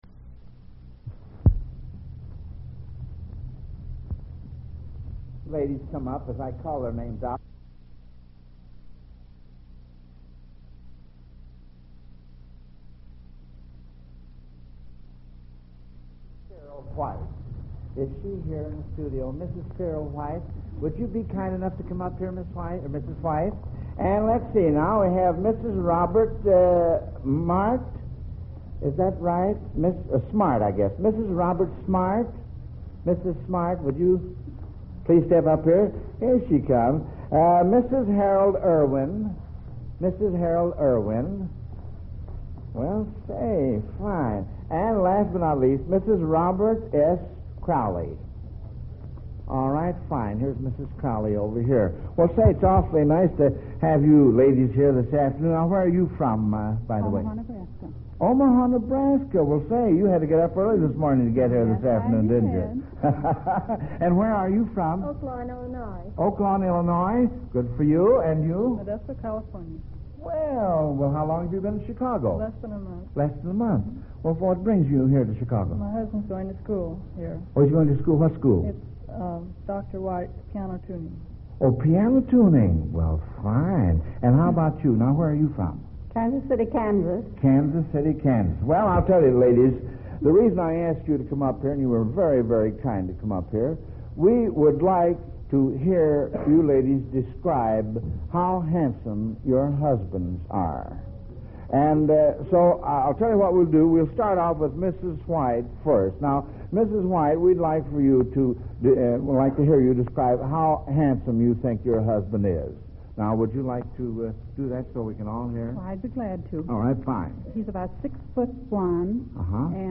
1950-09-xx-Prebroadcast-Warmup-for-Sept-24-1950-Program.mp3